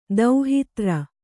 ♪ dauhitra